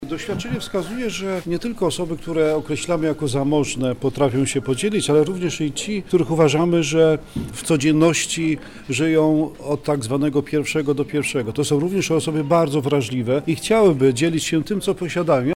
–mówi